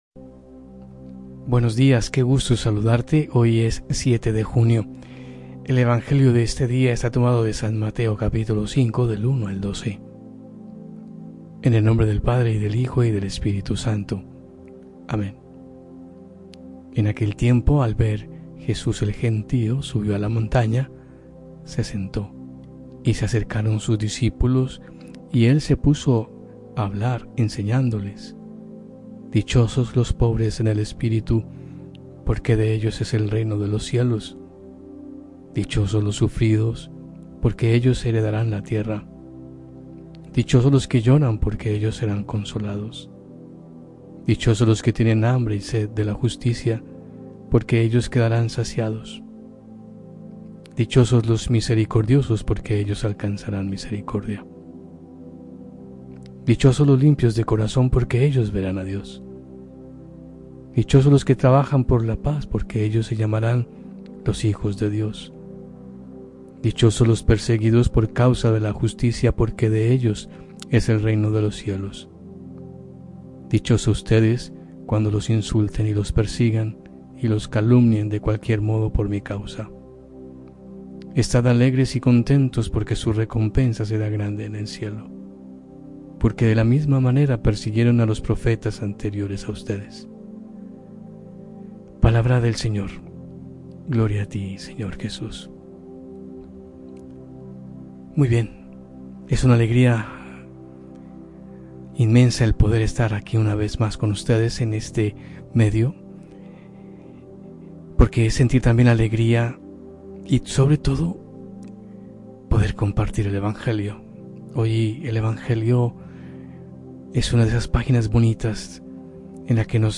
Reflexión del evangelio